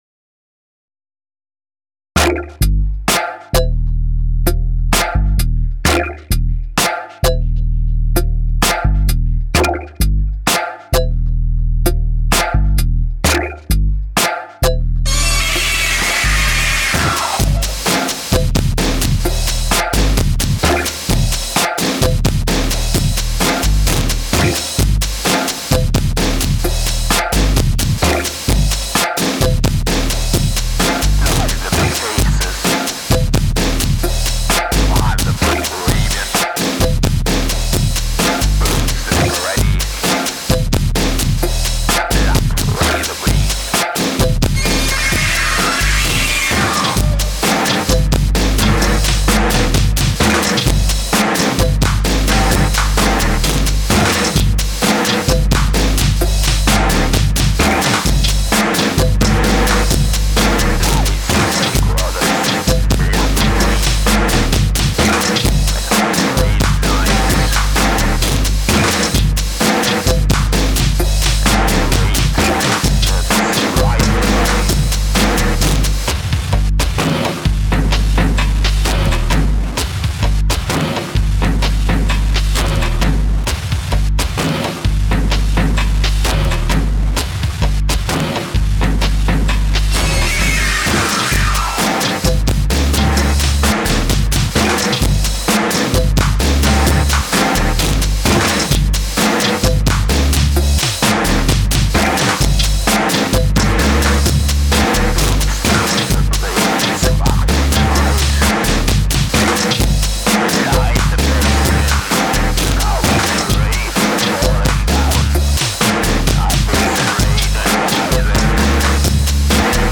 deutsche dub